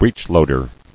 [breech·load·er]